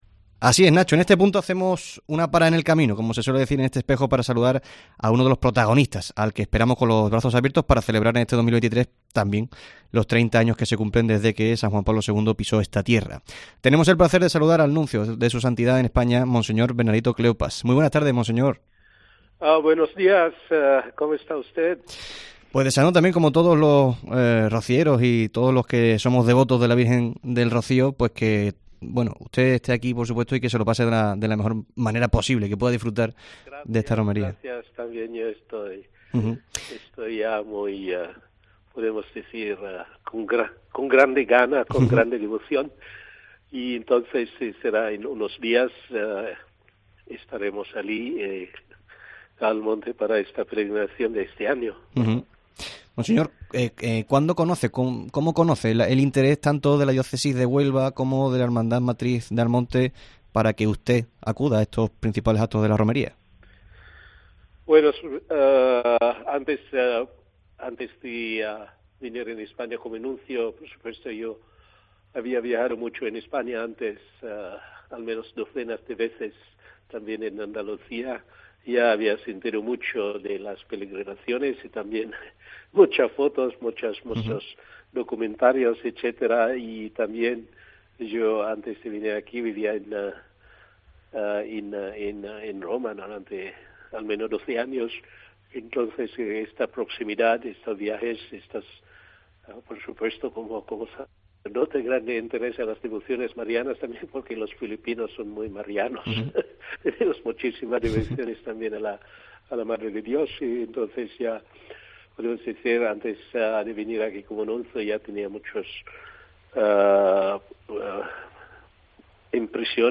Entrevista a Bernardito Cleopas, Nuncio de Su Santidad en España en El Espejo de COPE Huelva
AUDIO: Con motivo de la programación especial que COPE Huelva realiza en esta Romería del Rocío, en El Espejo entrevistamos al Nuncio de Su Santidad...